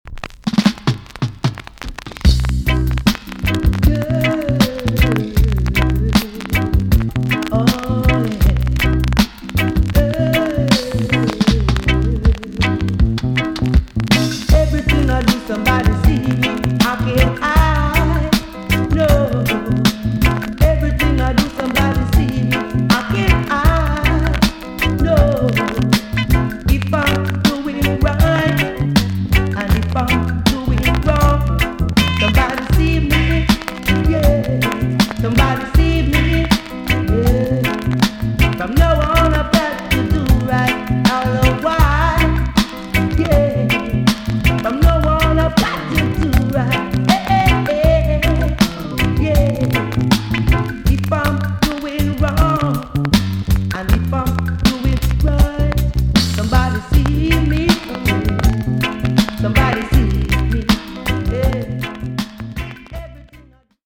TOP >80'S 90'S DANCEHALL
VG ok 全体的に軽いチリノイズが入ります。